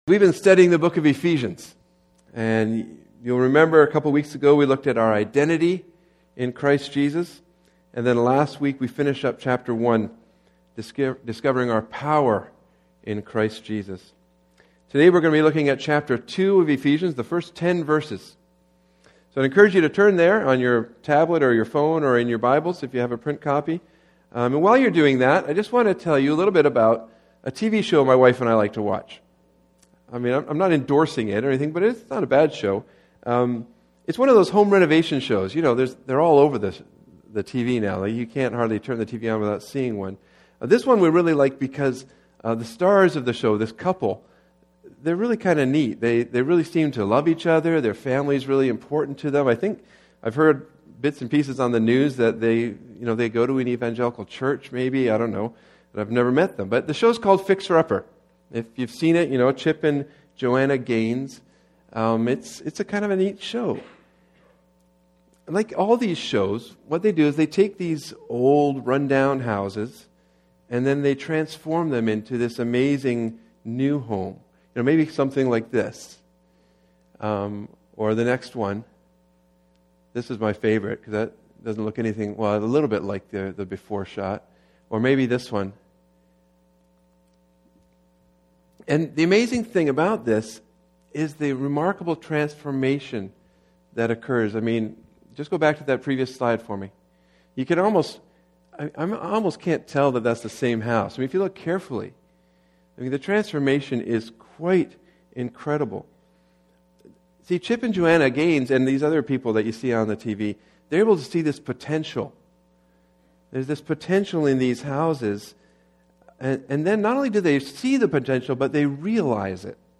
Sermons | Ritson Road Alliance Church